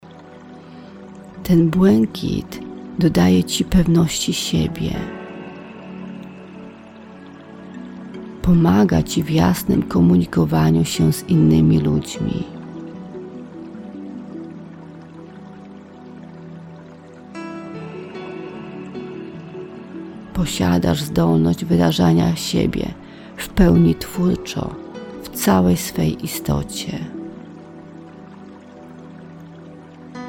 Medytacja prowadzona